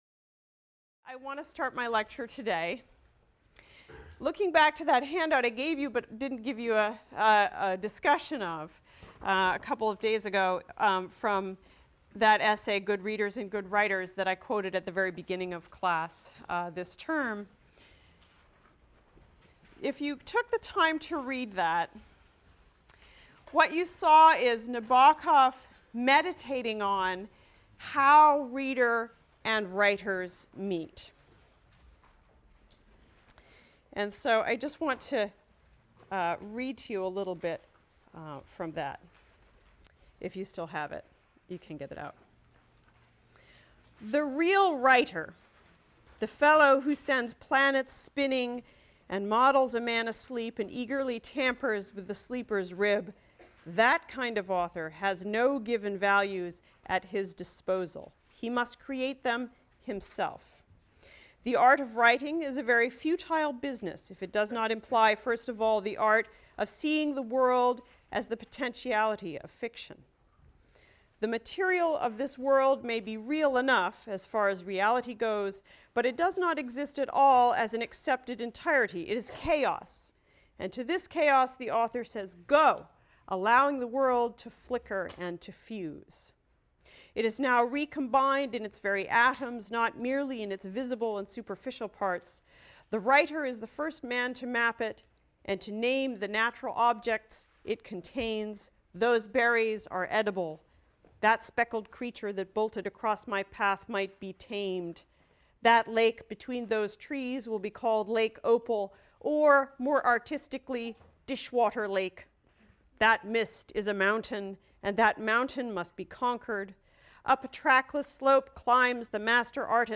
ENGL 291 - Lecture 7 - Vladimir Nabokov, Lolita (cont.)